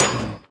metalhit1.ogg